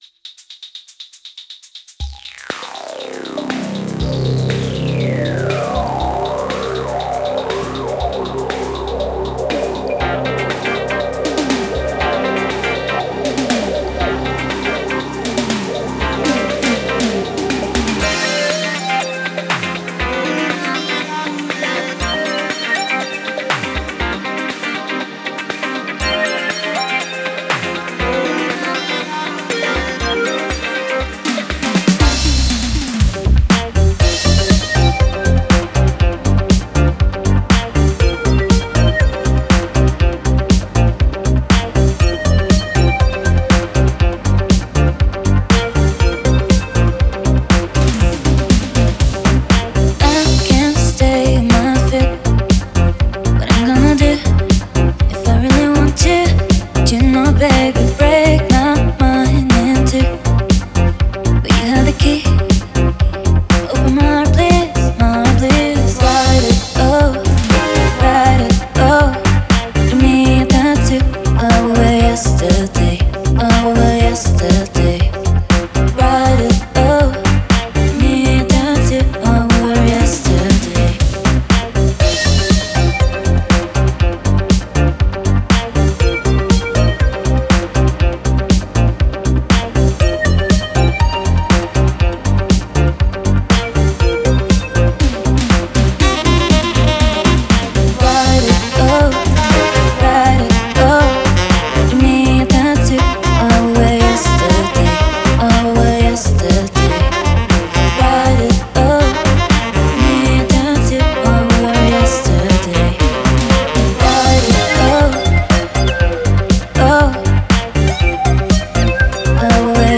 Лимитирование и максимайзинг дают свои плоды))).